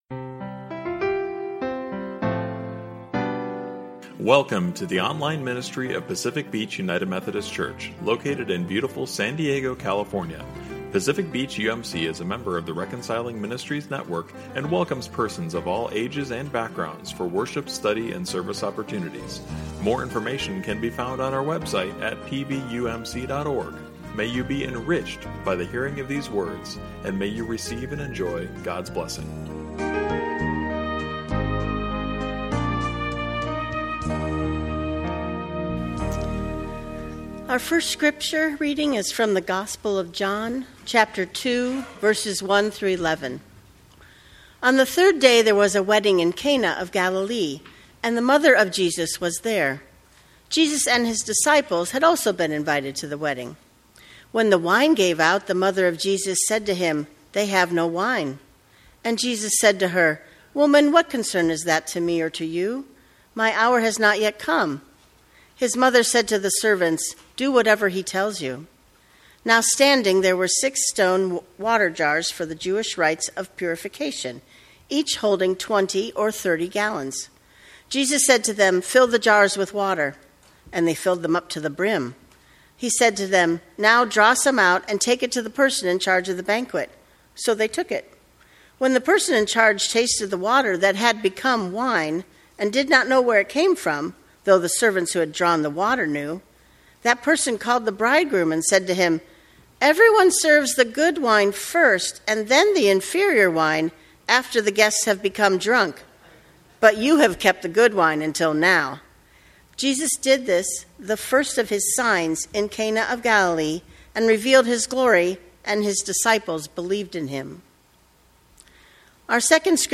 To hear a separate audio recording of the sermon, click on the Listen button above.